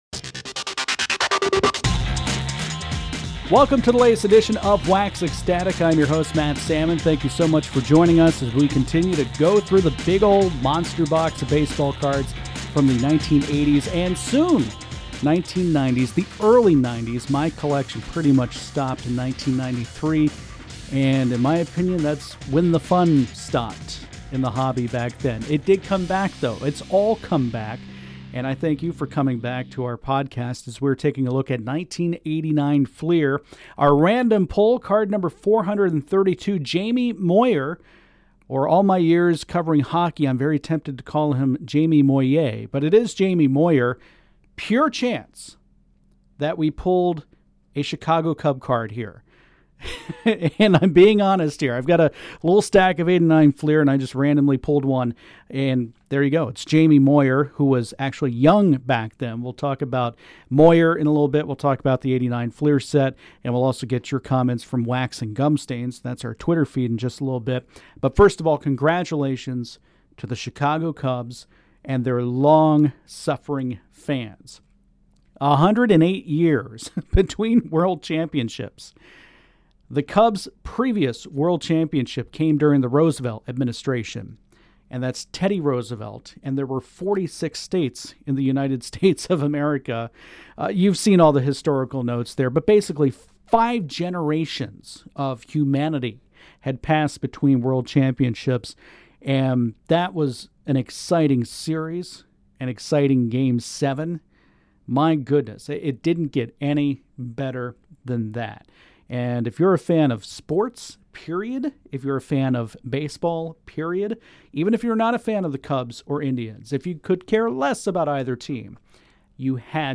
and he pulls out his Harry Caray impression when talking about Jamie Moyer's dating life.